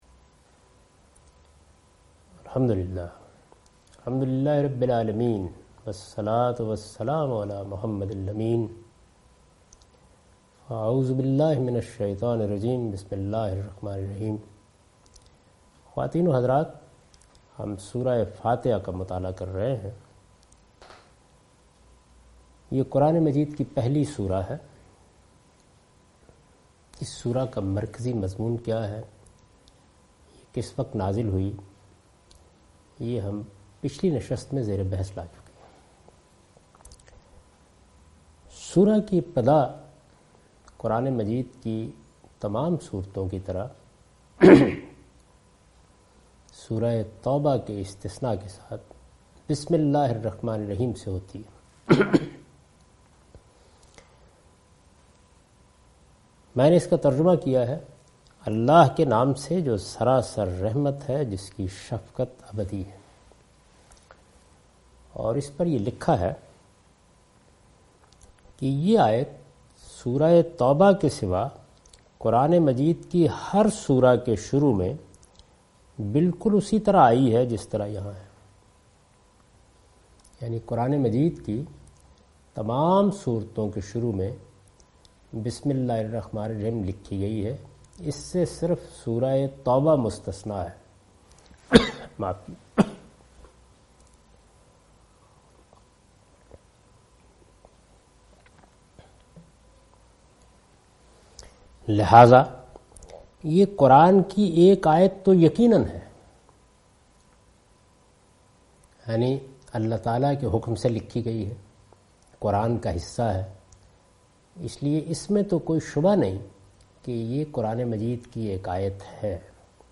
In this sitting he teaches first surah of 'Al Fatiha'. (Lecture recorded on 28th Feb. 2013)